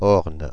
Prononciation
Synonymes frêne à fleurs Prononciation France (Paris): IPA: /ɔʁn/ Le mot recherché trouvé avec ces langues de source: français Les traductions n’ont pas été trouvées pour la langue de destination choisie.